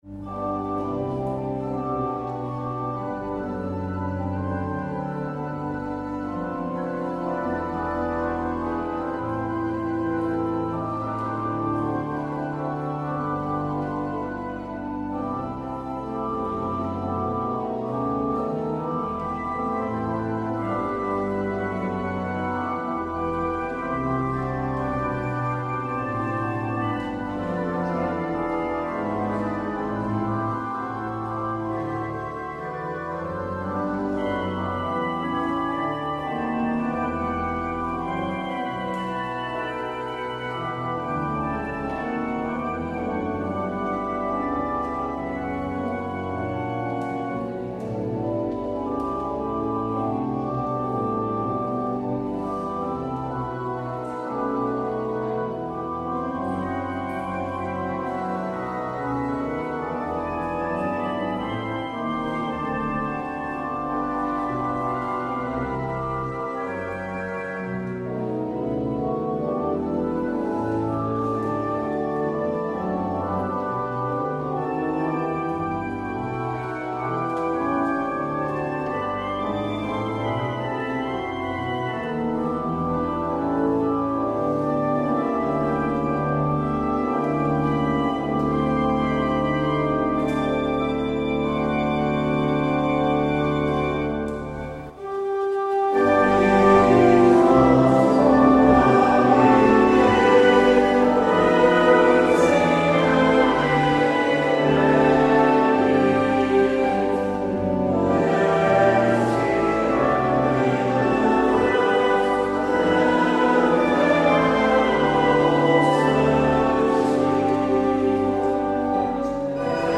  Luister deze kerkdienst hier terug: Alle-Dag-Kerk 25 juli 2023 Alle-Dag-Kerk https